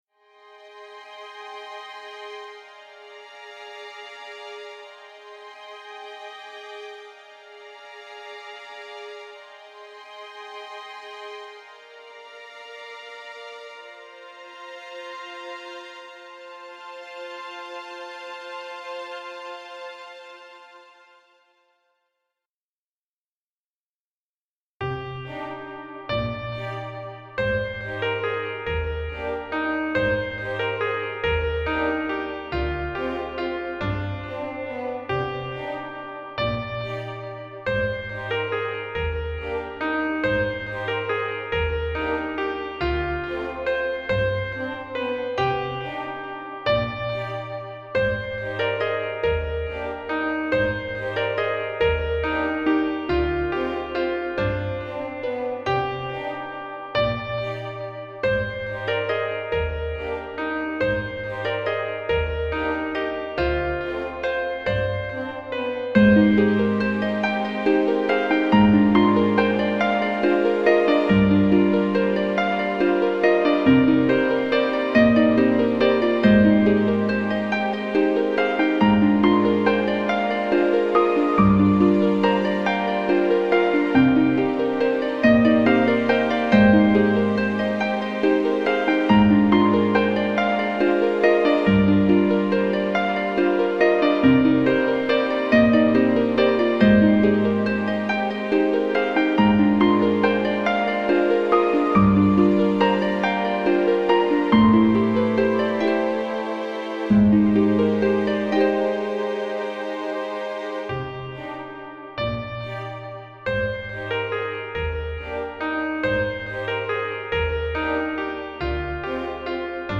切なく寂しいメロディー　広がりを感じるオーケストラver.